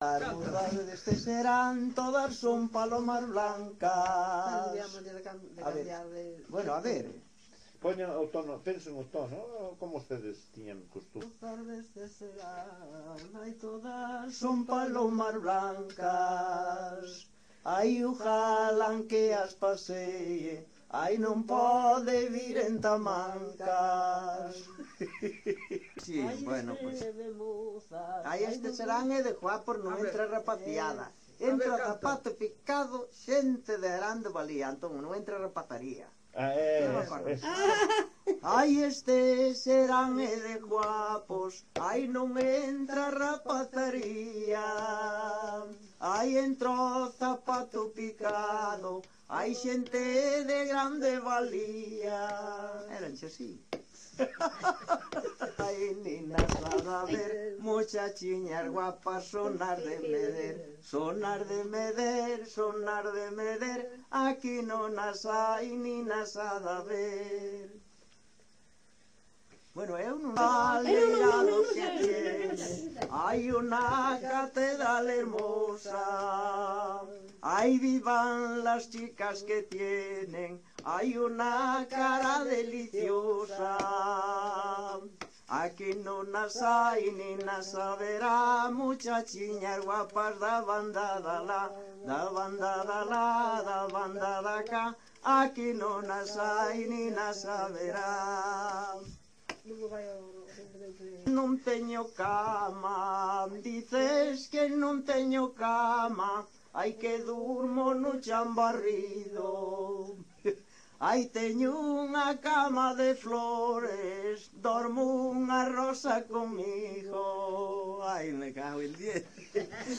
Coplas -
Notas da recolla